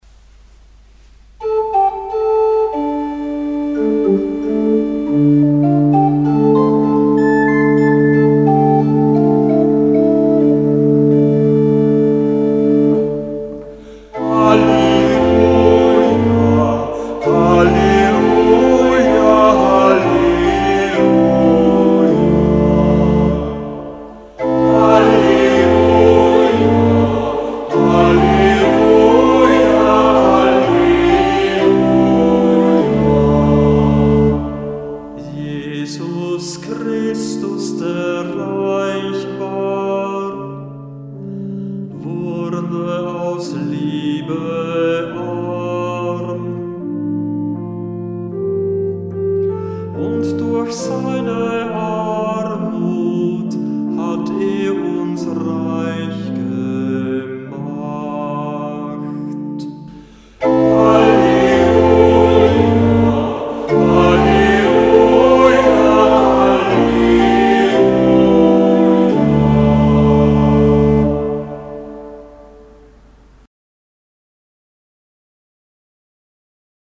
Ruf vor dem Evangelium - September 2025